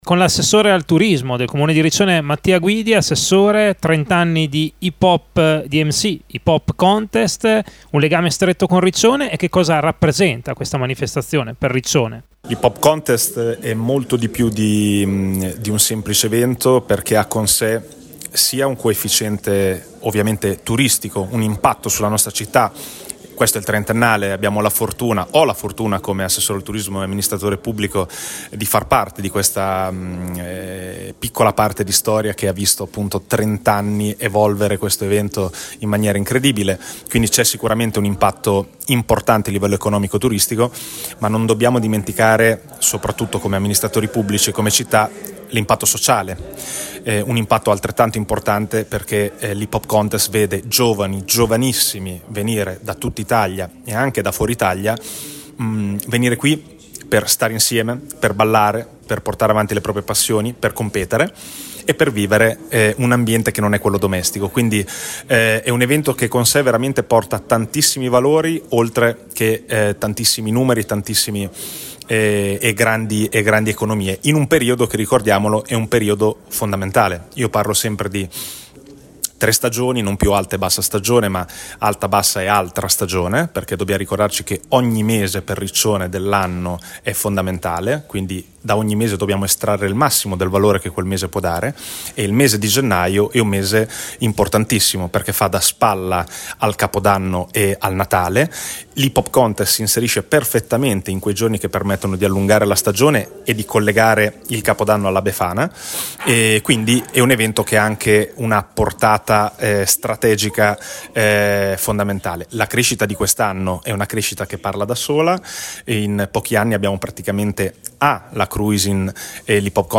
Ascolta l’intervista a Mattia Guidi (Assessore del turismo del Comune di Riccione):